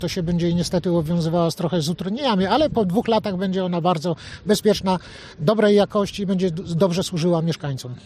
Należy uzbroić się w cierpliwość, ale pozytywne zmiany odczujemy wszyscy- mówił Marek Olbryś, wicemarszałek Województwa Podlaskiego: